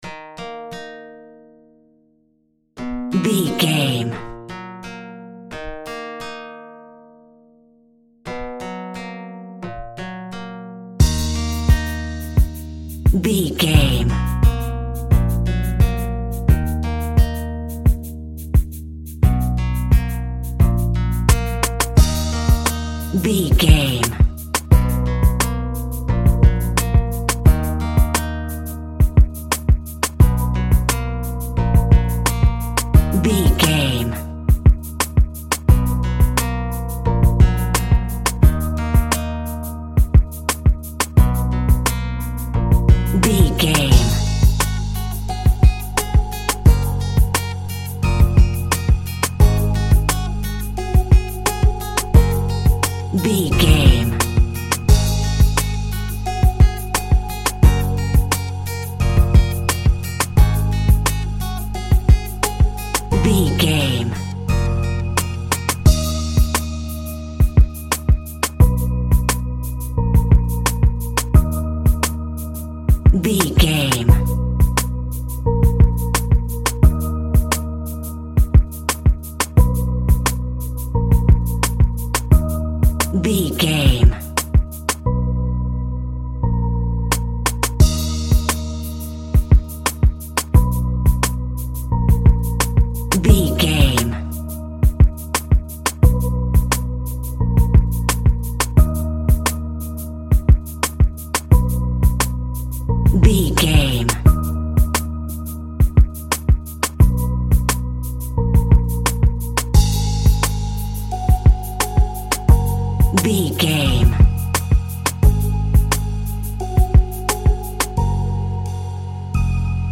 Laid and Light Rap.
Aeolian/Minor
E♭
hip hop
instrumentals
chilled
groove
hip hop drums
hip hop synths
piano
hip hop pads